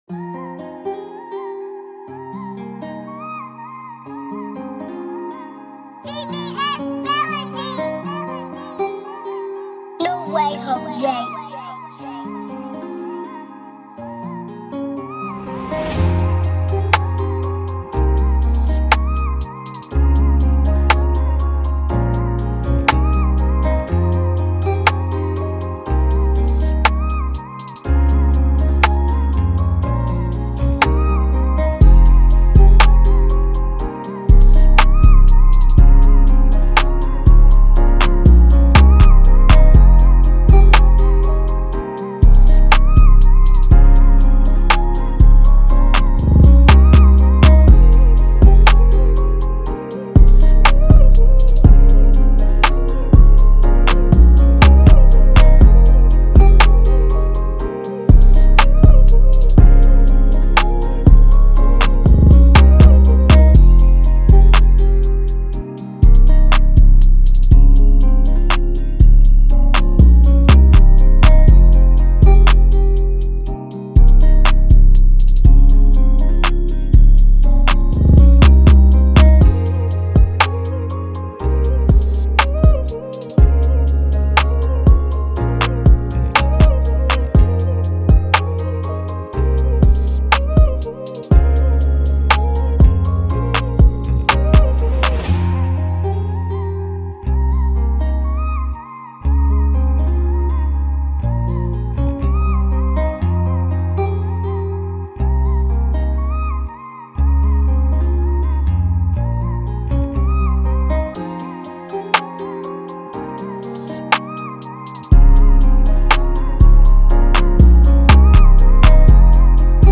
RnB Type Beat